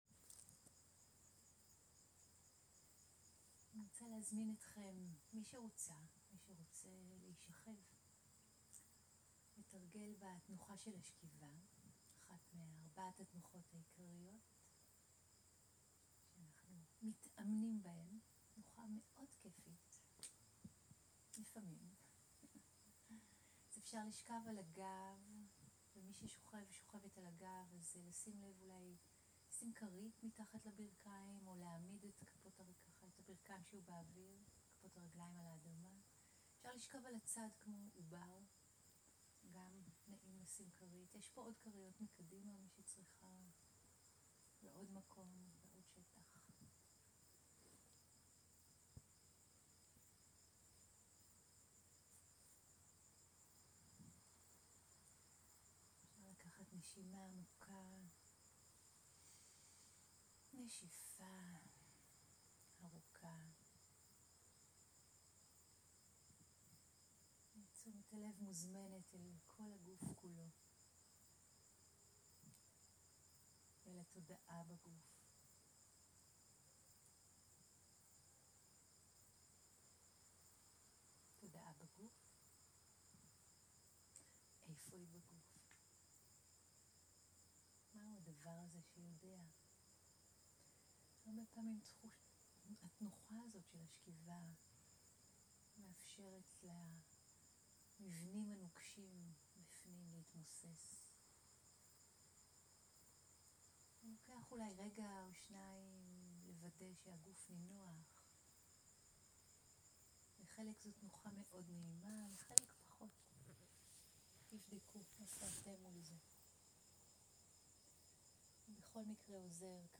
מדיטציה מונחית בשכיבה בחוץ
סוג ההקלטה: מדיטציה מונחית
איכות ההקלטה: איכות גבוהה